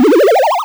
Powerup10.wav